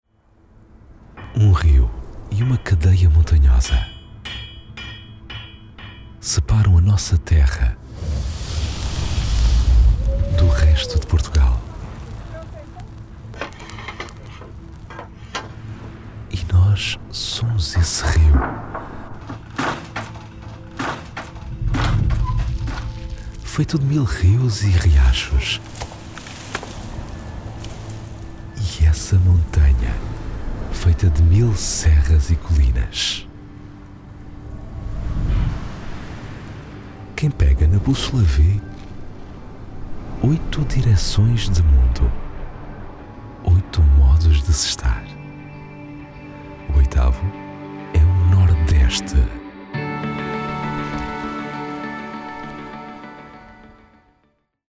I have a deep low male tone, calm and smooth, usually chosen for corporate, elearning, explainers, narration and commercials.
Warm Narration Portuguese
Words that describe my voice are Portuguese, deep, low tone.